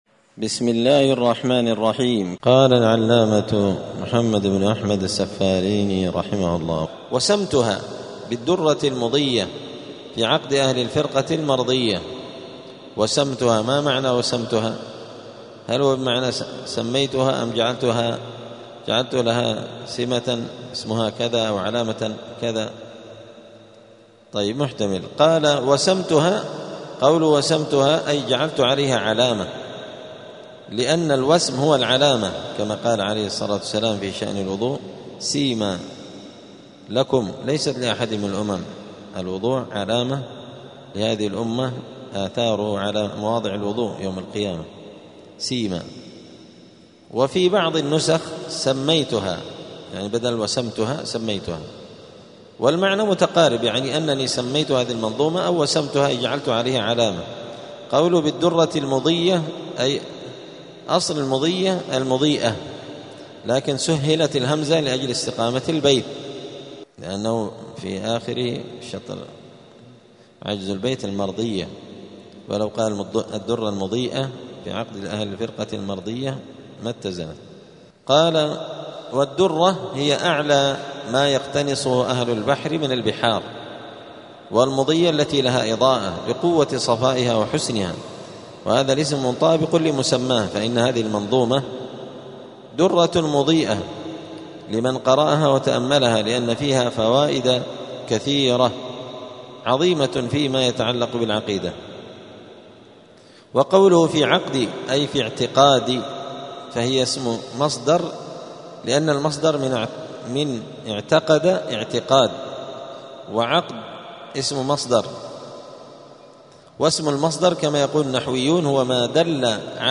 دار الحديث السلفية بمسجد الفرقان قشن المهرة اليمن
17الدرس-السابع-عشر-من-شرح-العقيدة-السفارينية.mp3